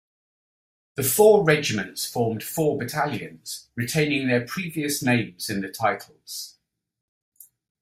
Pronounced as (IPA) /ɹɪˈteɪnɪŋ/